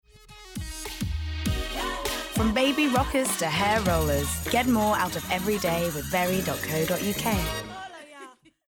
20/30's London/Neutral,
Contemporary/Natural/Earthy
Commercial Showreel